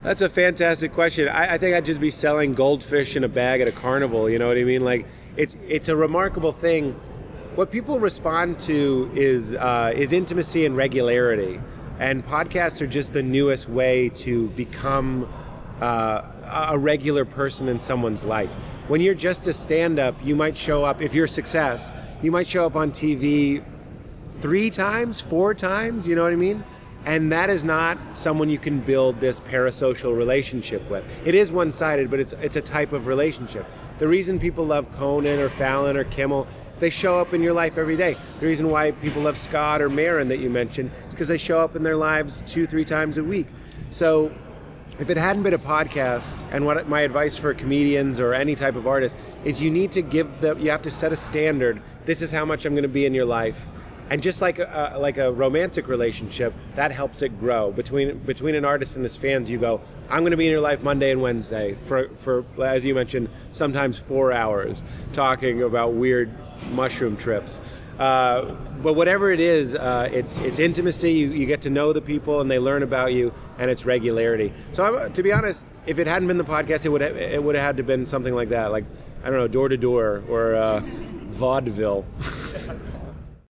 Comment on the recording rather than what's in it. While audio of the Q&A was recorded, most of the questions coming in were too far from the microphone to be heard well, so you can read each question below and then hear the response given by the panel.